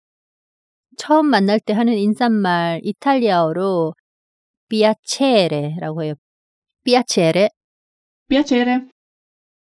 Piacere. ㅣ삐아체에레ㅣ